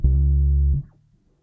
bass10.wav